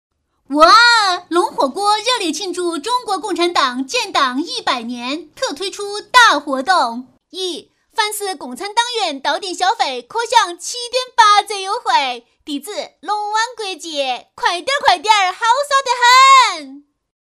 女28-方言配音-【龙火锅广告】- 四川话
女28-方言配音-【龙火锅广告】- 四川话.mp3